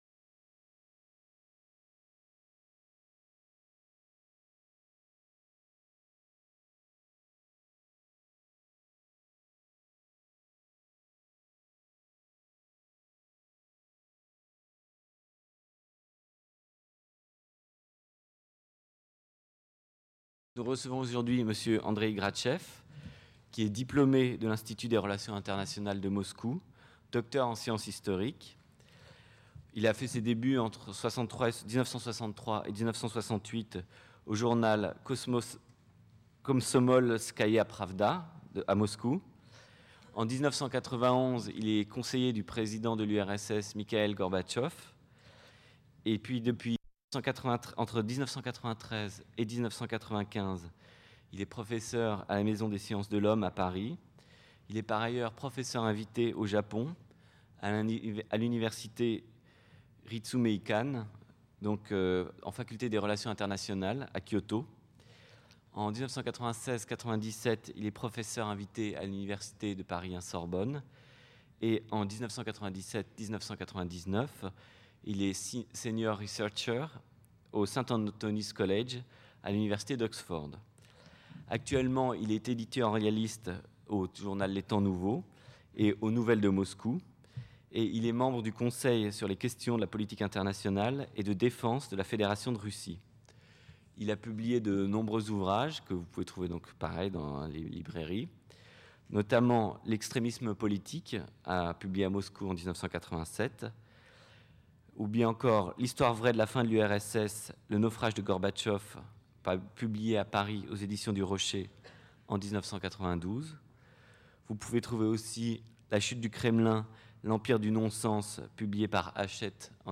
Les idées fortes de ma conférence : le XXème siècle a été marqué à deux reprises par des tournants historiques qui se sont produits en Russie.